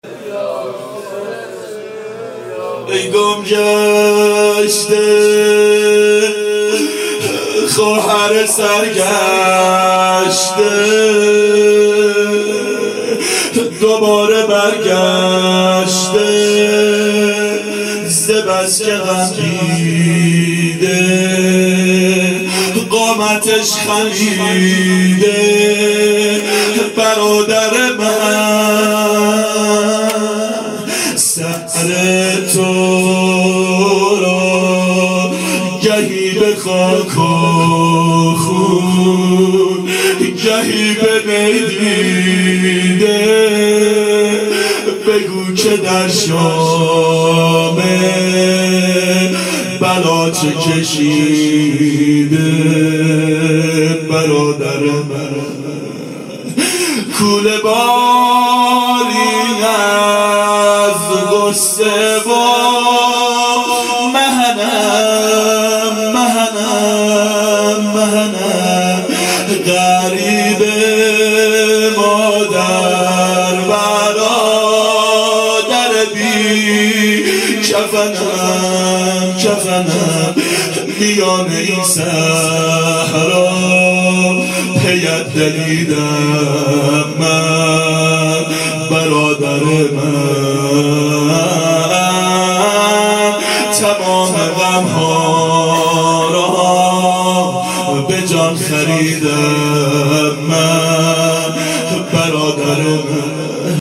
یک ار بعین ... روضه
ظهر اربعین سال 1390 محفل شیفتگان حضرت رقیه سلام الله علیها